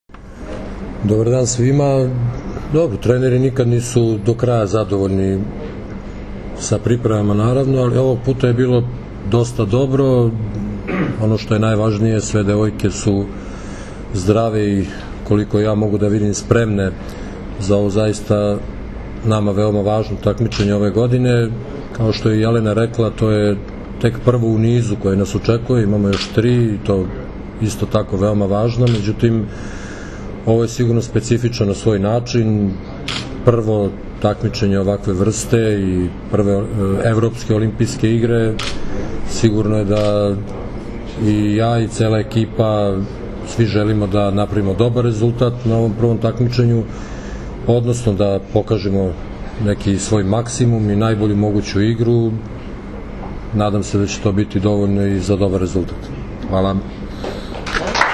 Na aerodromu “Nikola Tesla” jutros je održana konferencdija za novinare, na kojoj su se predstavnicima medija obratili Zoran Terzić, prvi trener seniorki Srbije, i Jelena Nikolić, kapiten Srbije.
IZJAVA ZORANA TERZIĆA